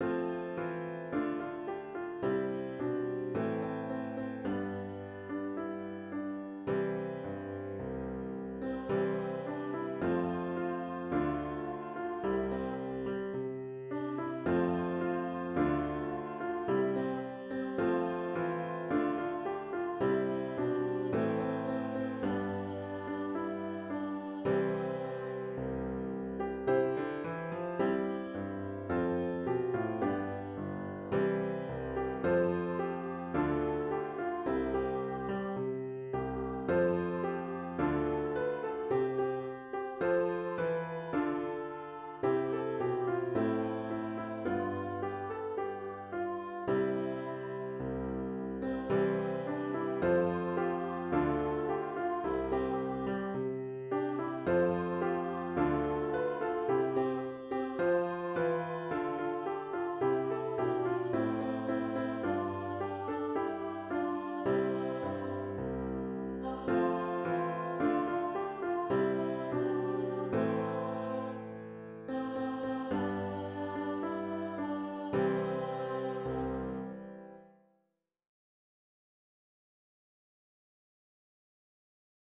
tobin-pledge-electronic-arrangement.wav